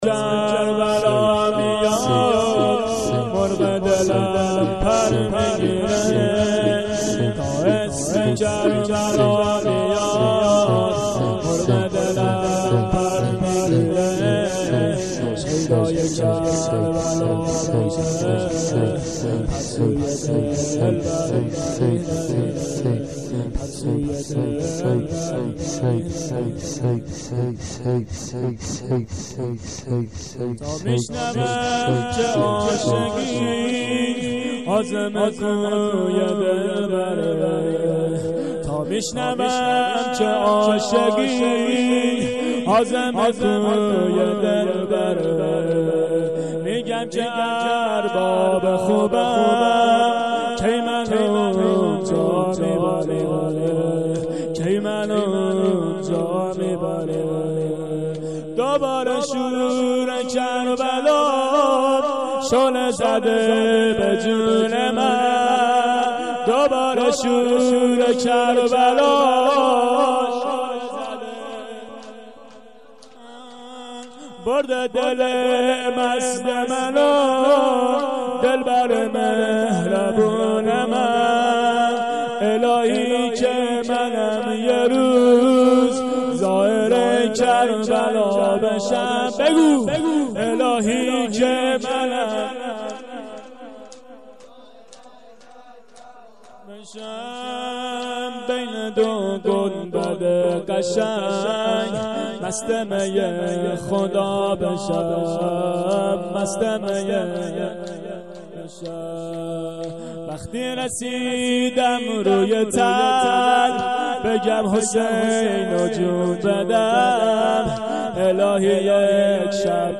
هیات العباس